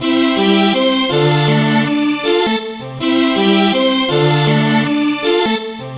Track 16 - Synth Strings 02.wav